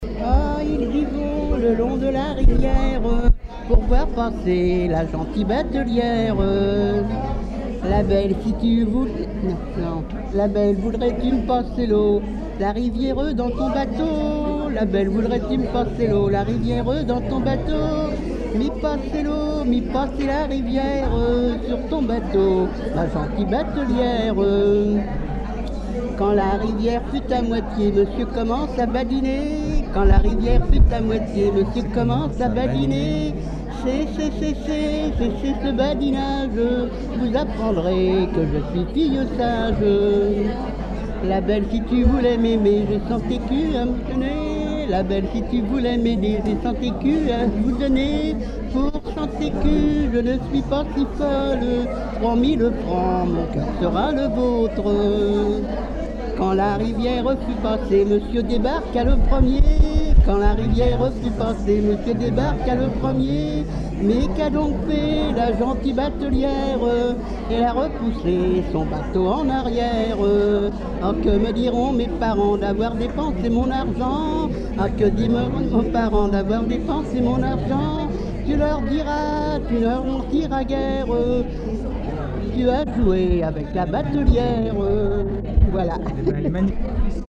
Enquête dans les Résidences de personnes âgées du Havre
Témoignages et chansons populaires
Pièce musicale inédite